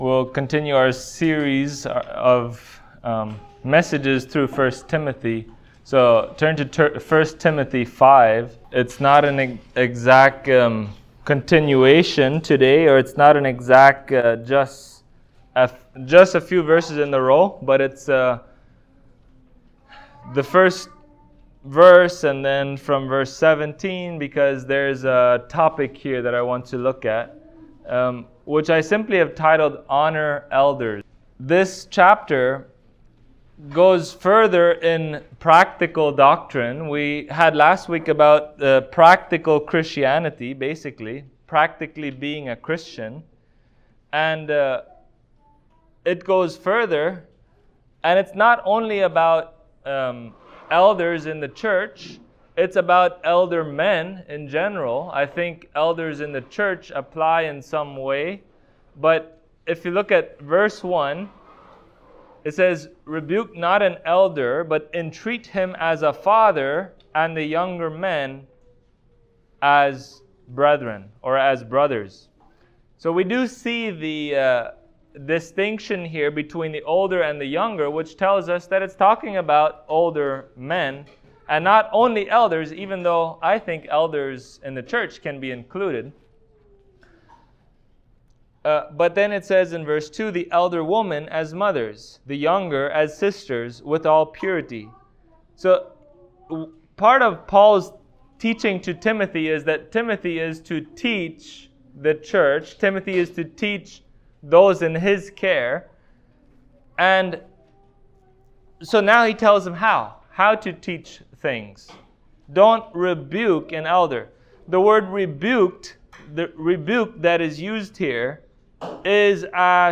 1Timothy 5:1 Service Type: Sunday Morning We are called to honor the elders that the Lord has placed over us.